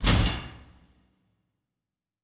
pgs/Assets/Audio/Fantasy Interface Sounds/Special Click 29.wav at master
Special Click 29.wav